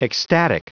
Prononciation du mot ecstatic en anglais (fichier audio)
Prononciation du mot : ecstatic
ecstatic.wav